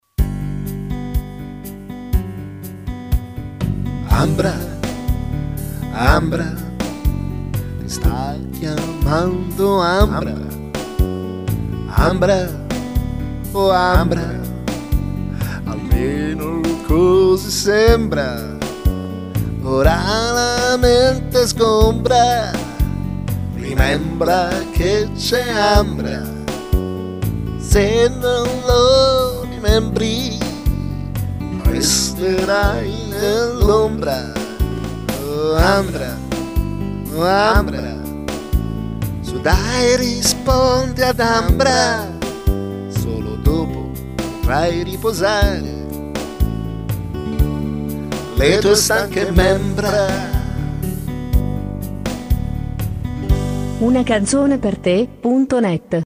Una suoneria personalizzata che canta il nome